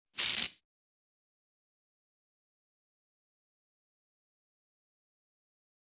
Ambient Sparking
Already 22khz Mono.
electric spark (1).wav
electric_spark_1_660.wav